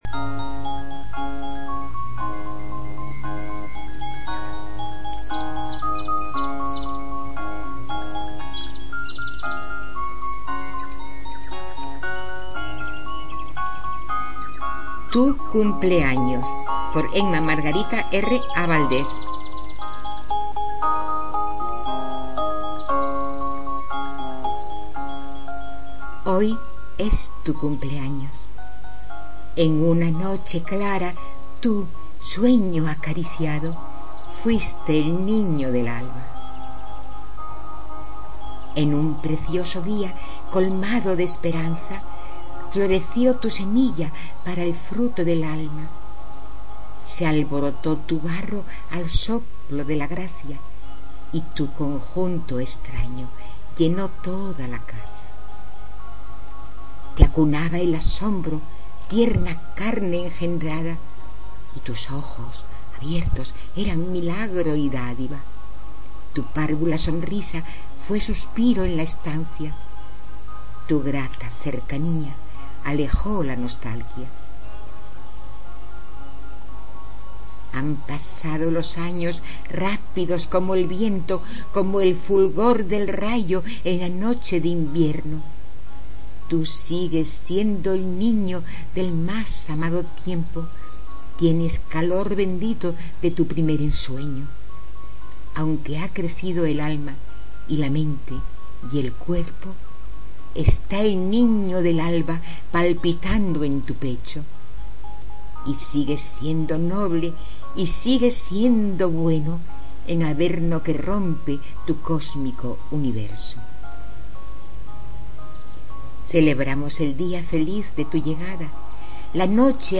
En mp3, recitada por la autora.